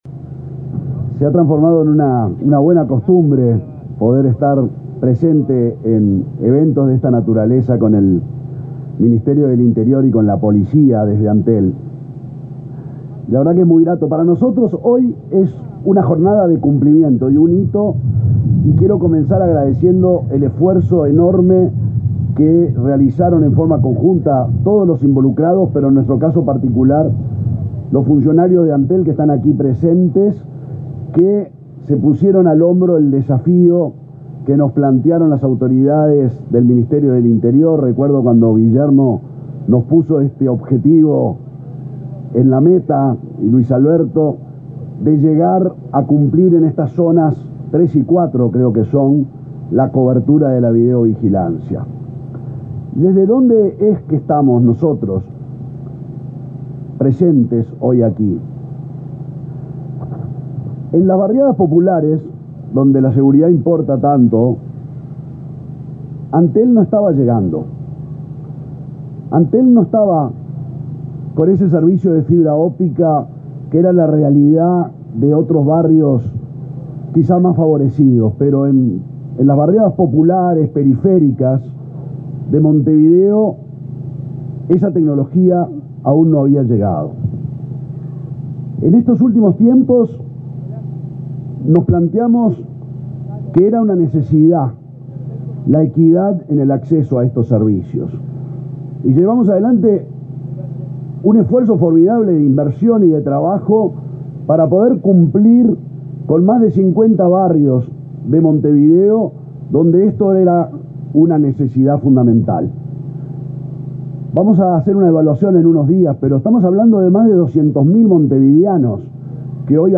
Palabras de autoridades en acto del Ministerio del Interior